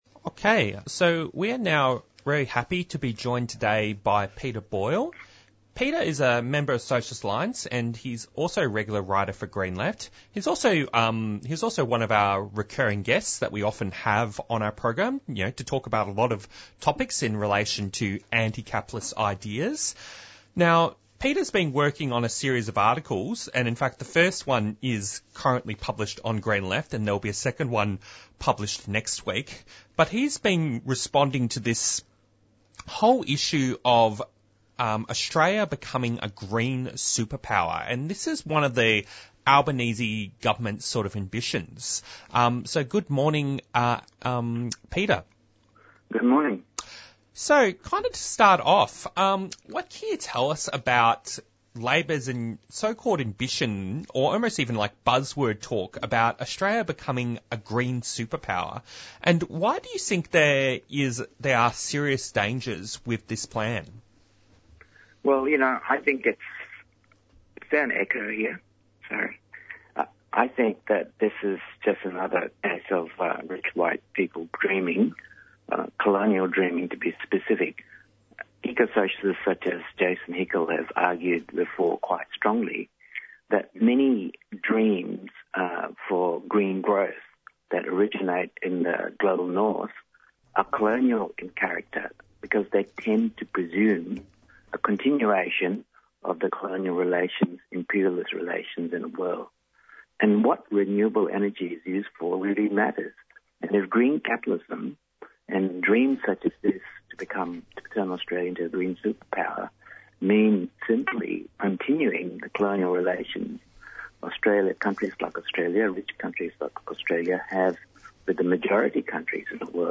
Headline newsreports from the presenters
Interviews and Discussions
Recording of a talk by Merri-bek councillor Sue Bolton speaking at a snap rally, on August 1 in Naarm/Melbourne which was called in response to the escalation of conflict in the Middle-East by Israel.